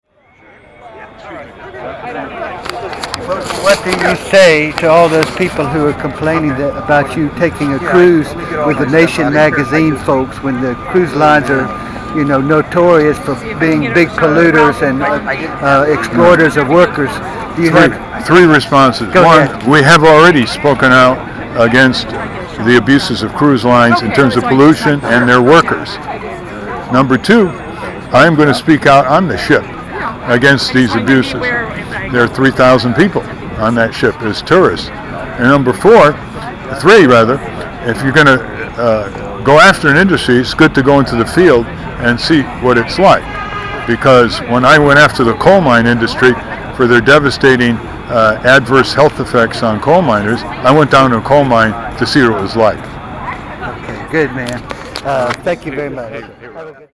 Ralph Nader Joins Congressional Watchdog Group at San Francisco Federal Building : Indybay
§Ralph Nader explains a project that has puzzles some leftists.